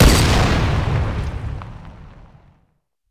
plasrifle.wav